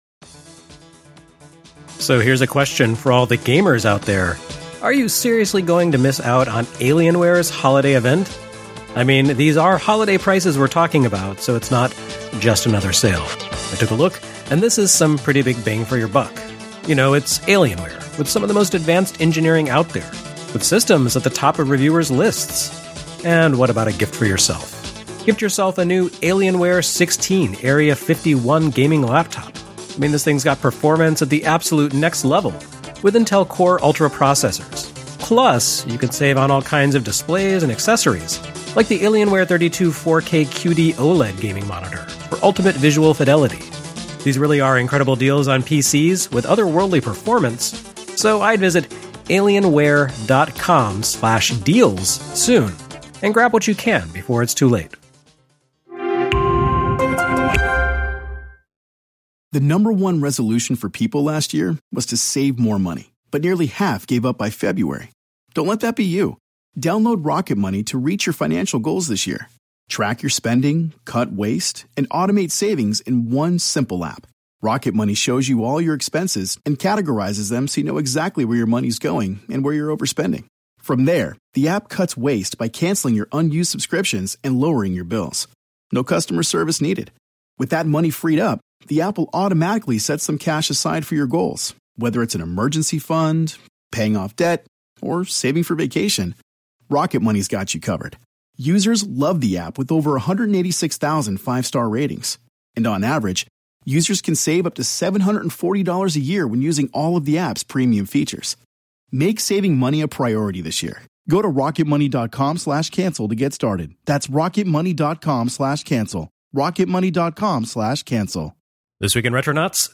with me this week on Skype